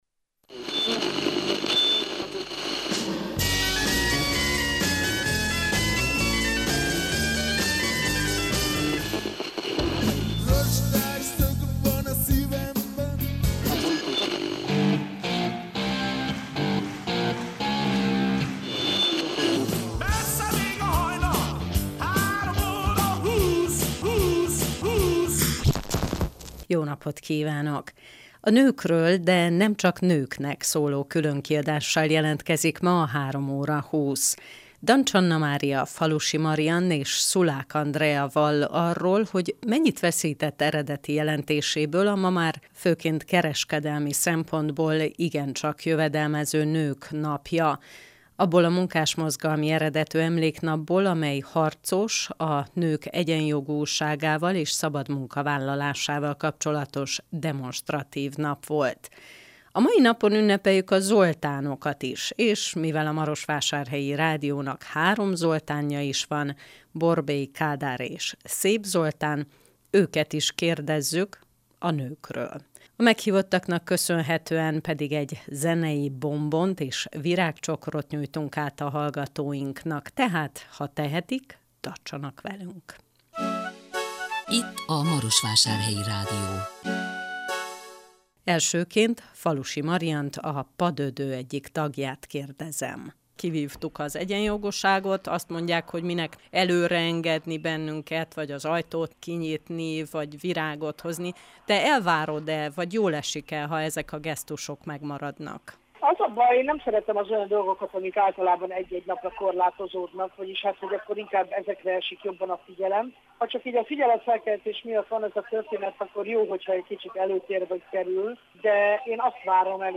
A meghívottaknak köszönhetően egy zenei csokrot nyújtunk át a hallgatóknak.